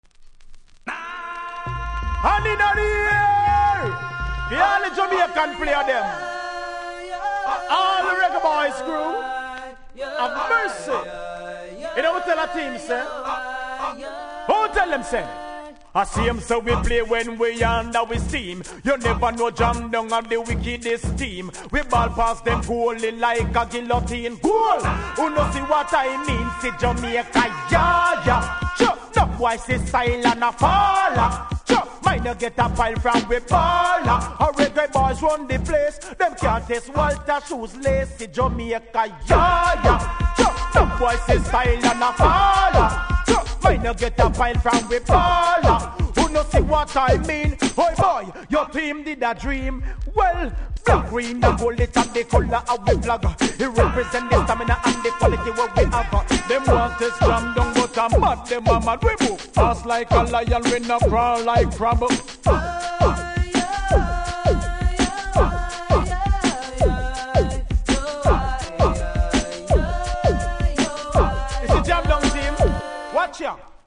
REGGAE 90'S